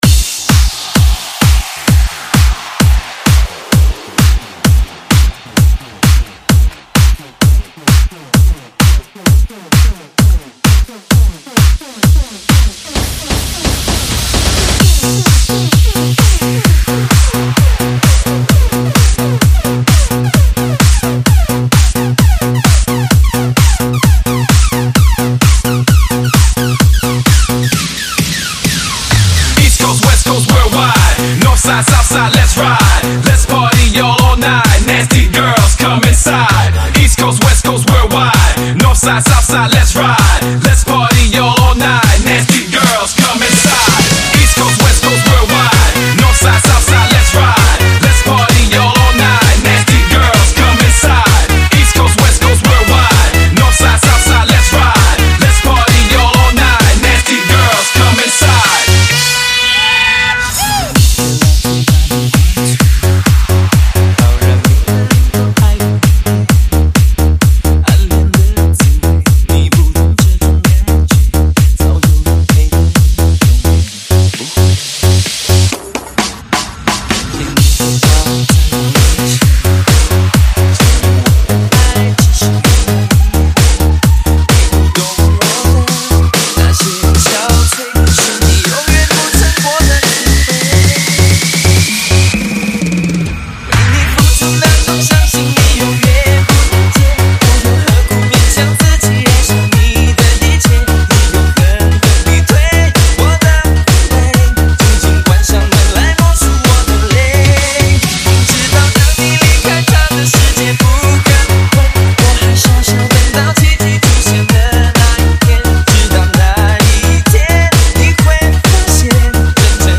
4天前 DJ音乐工程 · 沈阳风 2 推广